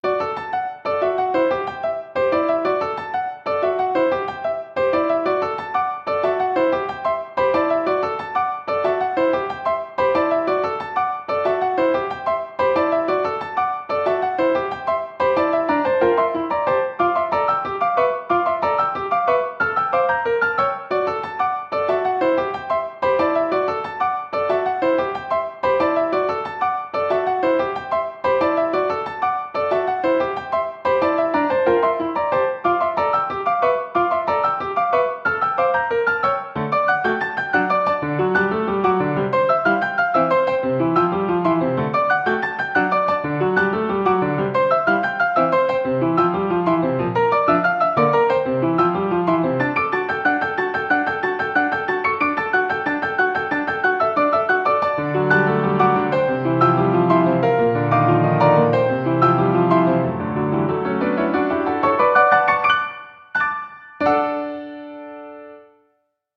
ogg(R) - 軽やか リズミカル シンプル
何かを説明するシーンに合いそうな規則的な曲調。